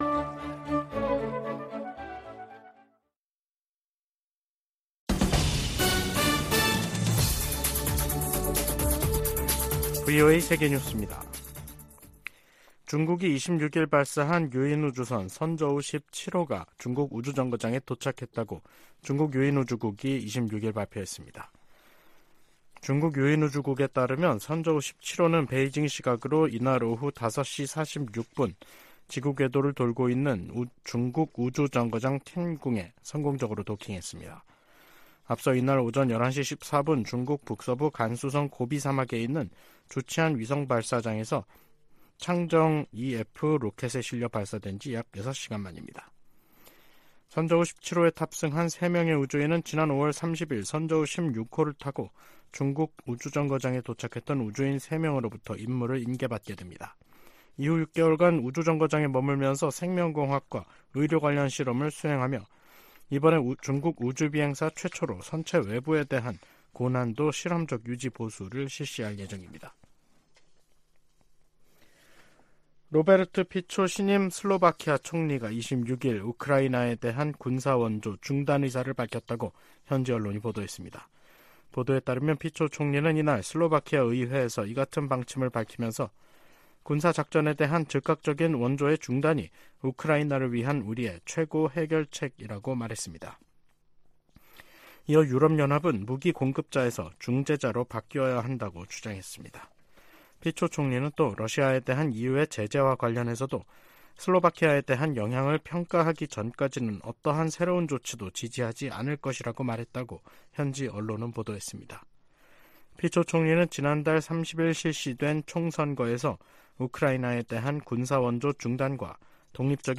VOA 한국어 간판 뉴스 프로그램 '뉴스 투데이', 2023년 10월 26일 3부 방송입니다. 미국, 한국, 일본 세 나라 외교장관들이 북한과 러시아 간 불법 무기 거래를 규탄하는 공동성명을 발표했습니다. 북한은 유엔에서 정당한 우주 개발 권리를 주장하며 사실상 우주발사체 발사 시도를 계속하겠다는 뜻을 내비쳤습니다.